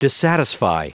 Prononciation du mot dissatisfy en anglais (fichier audio)
Prononciation du mot : dissatisfy